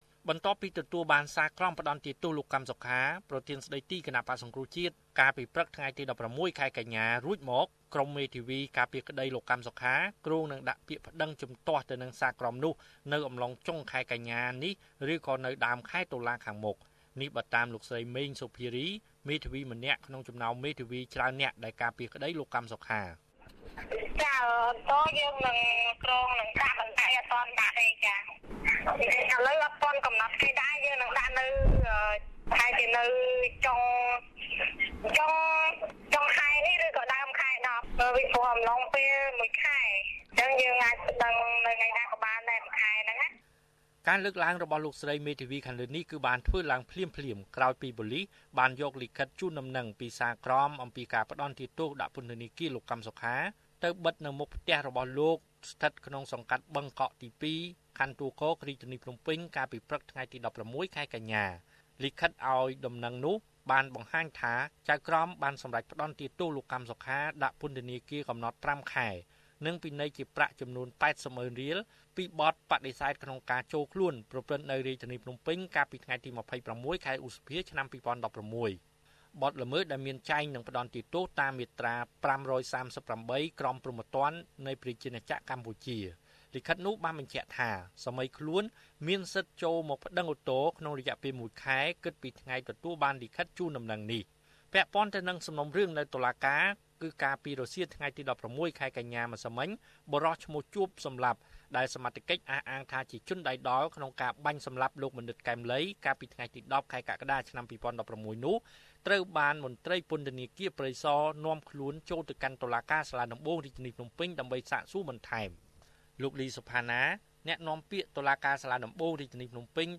បន្ទាប់ពីទទួលបានសាលក្រមផ្តន្ទាទោសលោក កឹមសុខា ប្រធានស្តីទីគណបក្សសង្គ្រោះជាតិរួចមក កាលពីព្រឹកថ្ងៃទី១៦ ខែកញ្ញា ឆ្នាំ២០១៦ ក្រុមមេធាវីការពារក្តីលោកកឹមសុខា គ្រោងនឹងដាក់ពាក្យប្តឹងជំទាស់នឹងសាលក្រមនោះ នៅអំឡុងចុងខែនេះឬដើមខែក្រោយ។សូមចុចសំឡេងដើម្បីស្តាប់របាយការណ៍លំអិត៖